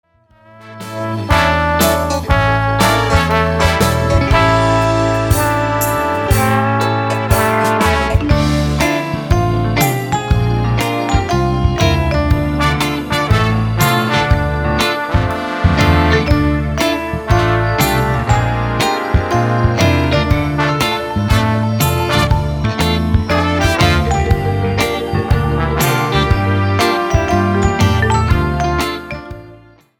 POP  (03,28)